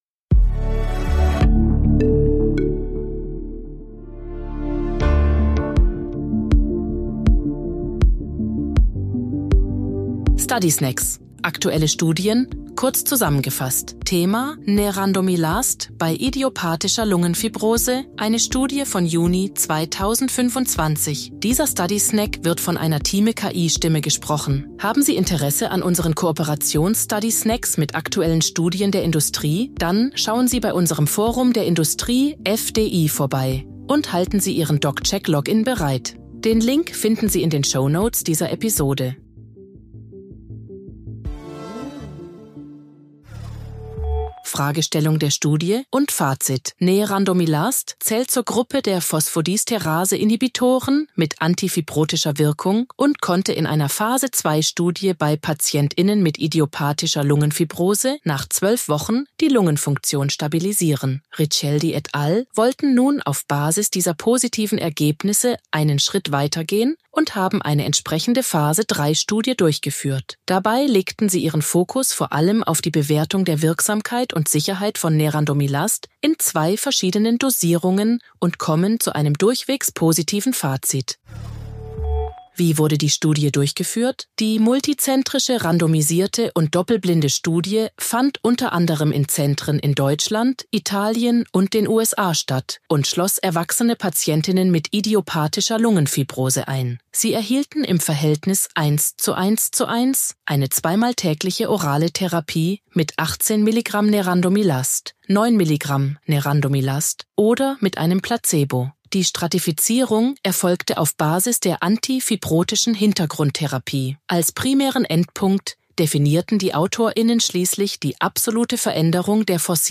Hilfe von künstlicher Intelligenz (KI) oder maschineller